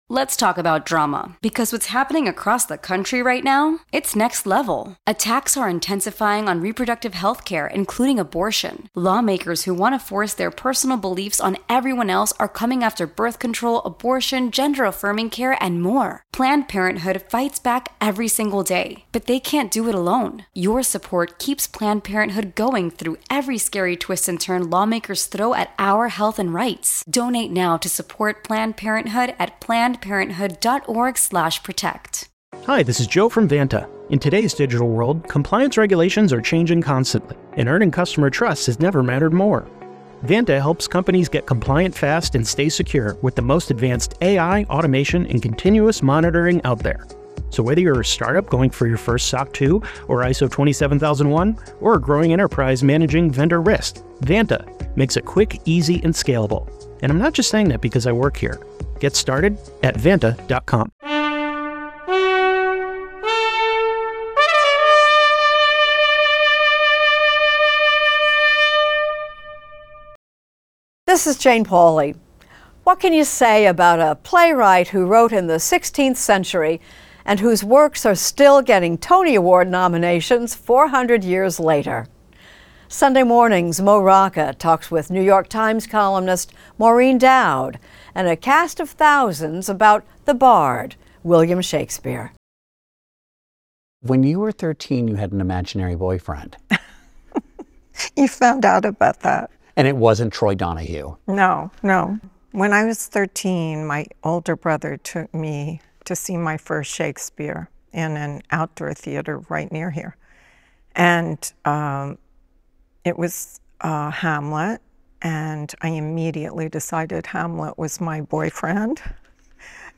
Correspondent Mo Rocca sits down with Pulitzer Prize-winning New York Times columnist Maureen Dowd at the Folger Shakespeare Library, in Washington, D.C., to talk about her fascination with the work of William Shakespeare, a fascination that began as a teenager with her crush on Hamlet. She also compares today's political figures to Shakespeare's characters and their use/abuse of power and the failures of leadership.